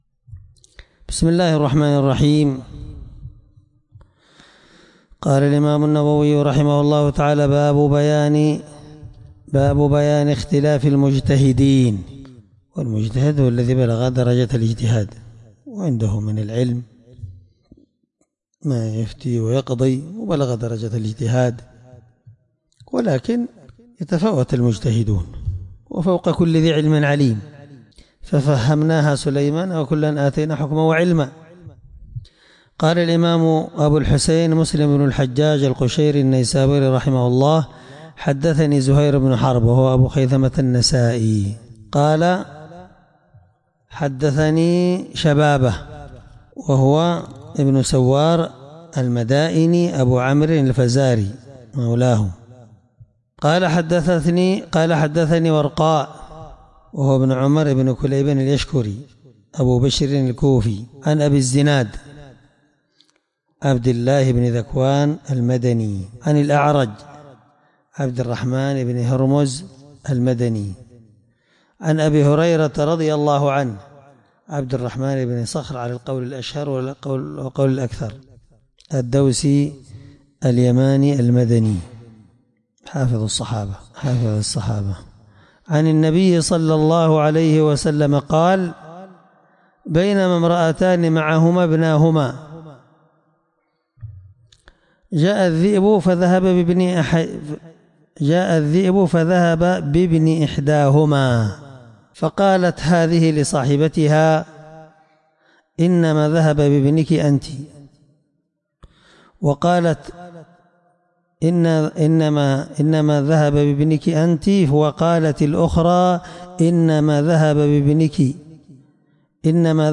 الدرس11من شرح كتاب الأقضية الحدود حديث رقم(1720) من صحيح مسلم